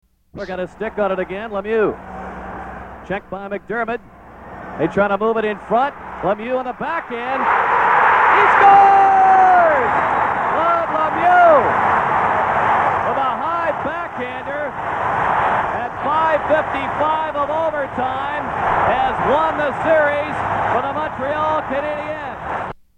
Play-by-Play